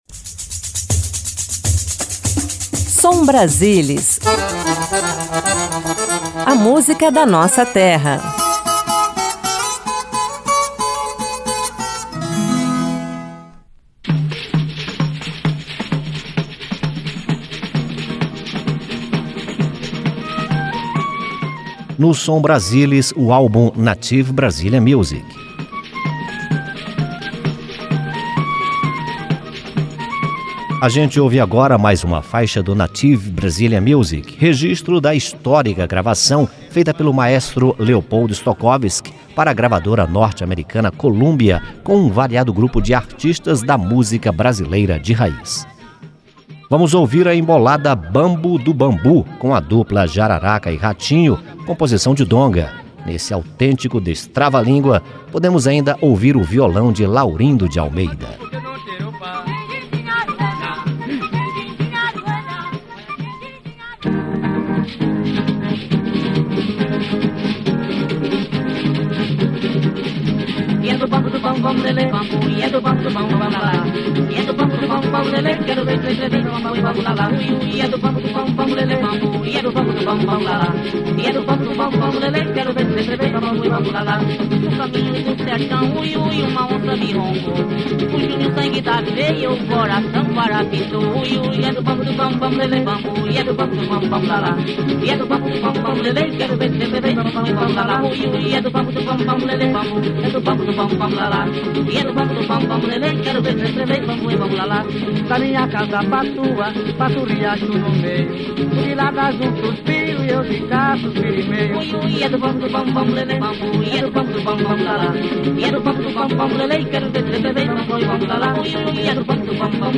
Música orquestrada
Cantos indígenas
Música folclórica